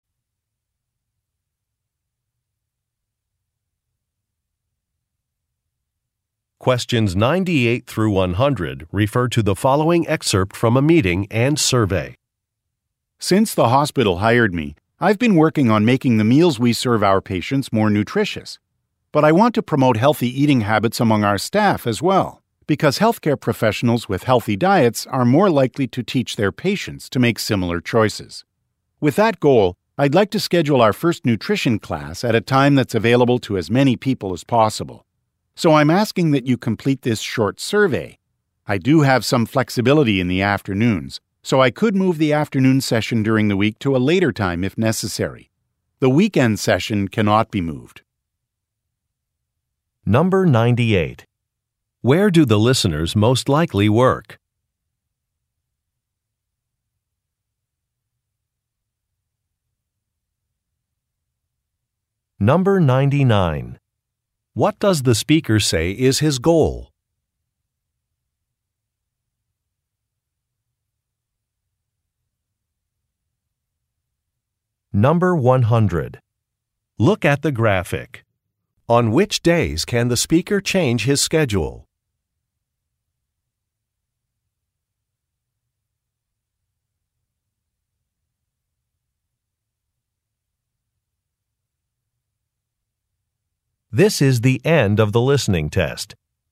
Question 98 - 100 refer to following talk: